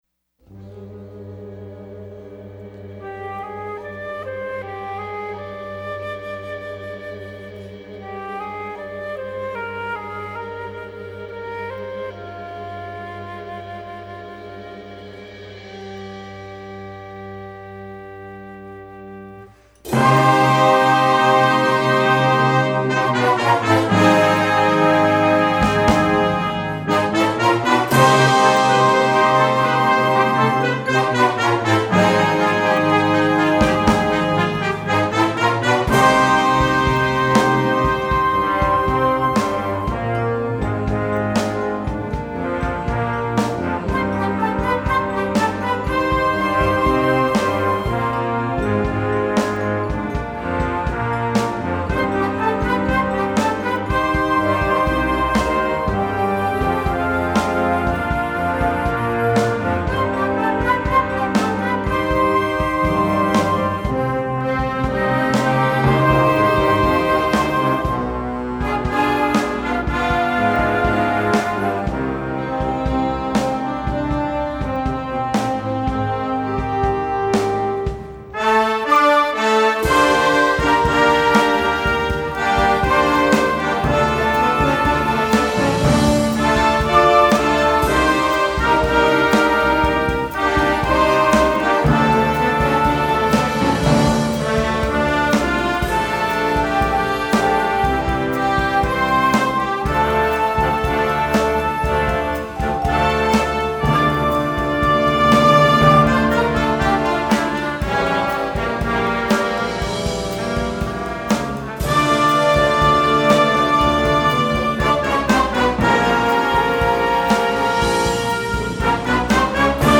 Voicing: Flex Band